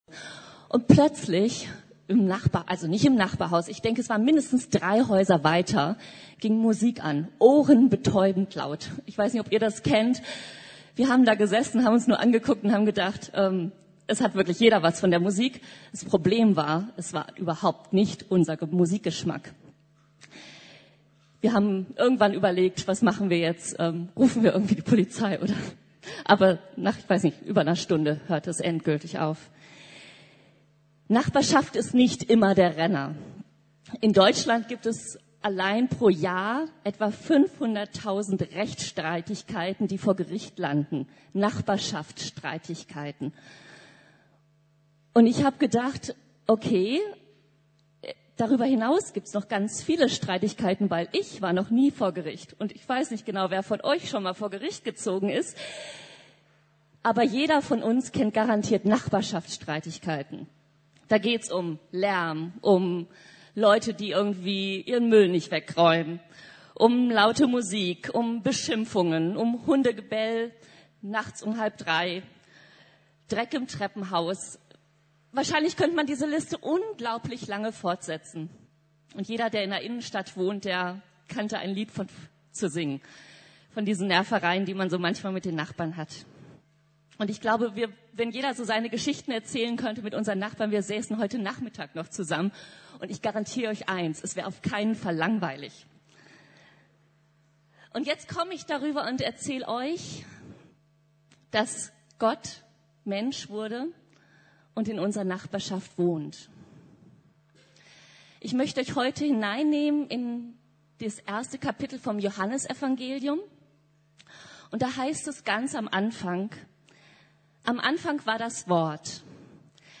Gott wurde Mensch und wohnte in unserer Nachbarschaft ~ Predigten der LUKAS GEMEINDE Podcast